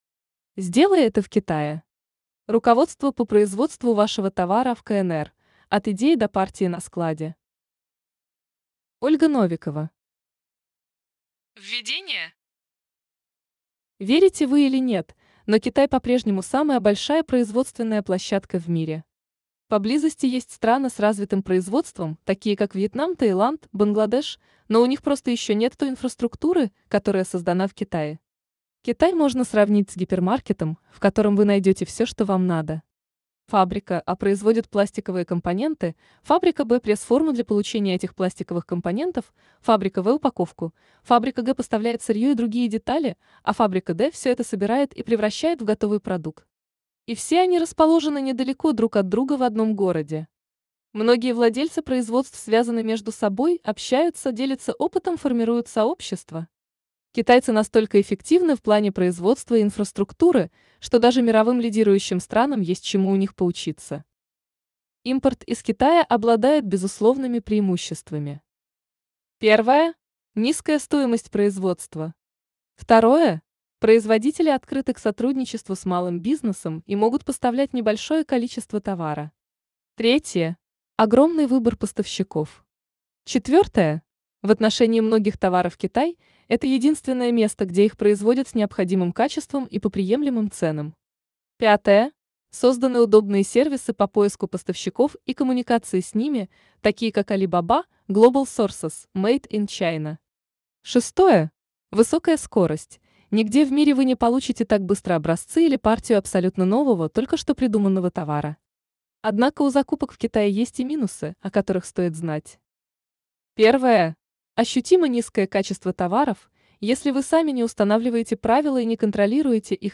Аудиокнига Сделай это в Китае!